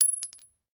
pistol_generic_7.ogg